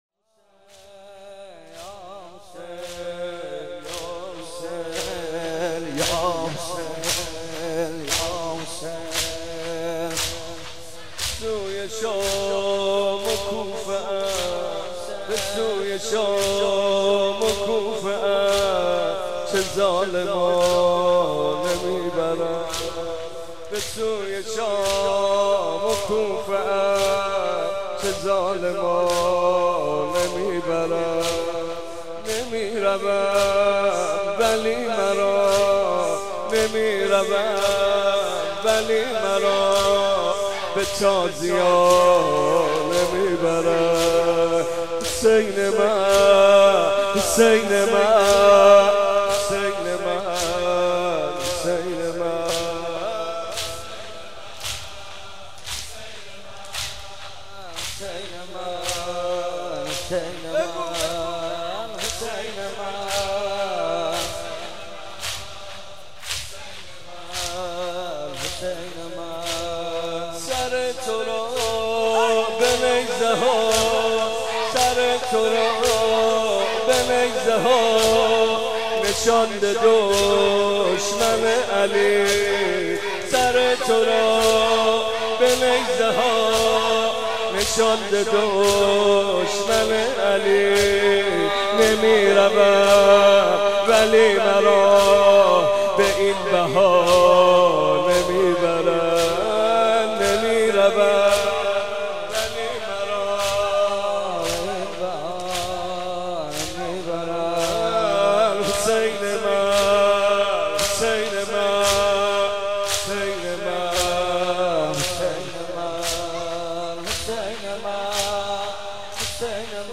بخش اول - مناجات دریافت بخش دوم - روضه دریافت بخش سوم- نوحه دریافت